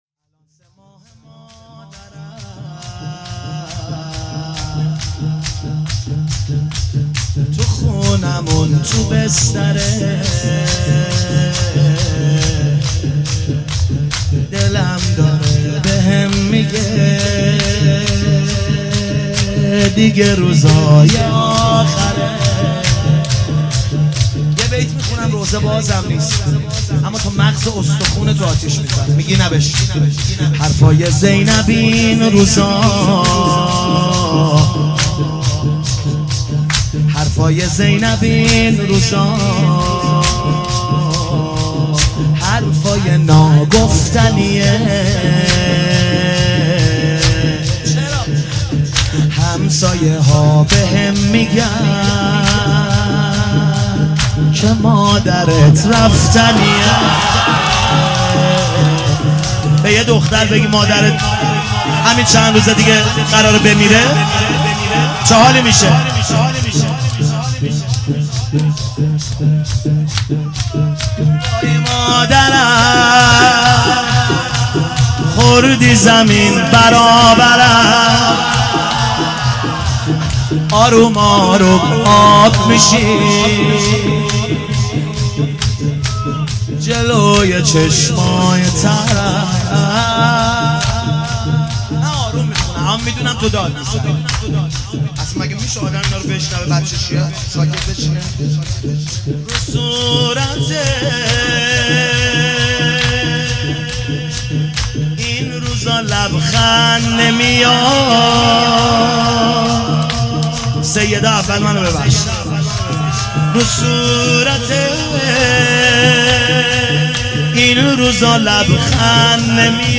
مداحی و نوحه
(شور)